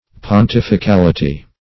Search Result for " pontificality" : The Collaborative International Dictionary of English v.0.48: Pontificality \Pon*tif`i*cal"i*ty\, n. The state and government of the pope; the papacy.